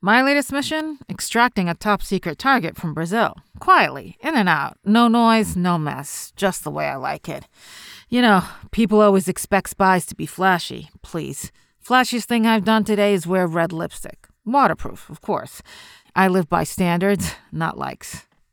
female voiceover artist
Character Samples
Spy.mp3